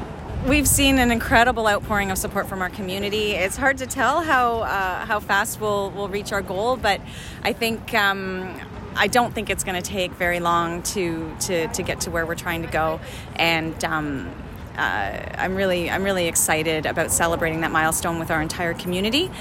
At a small media event in front of the current Prince Edward County Memorial Hospital, where the foundation has a fundraising thermometer tracking progress, it was announced that the John M. and Bernice Parrott foundation donated $750,000 to the cause.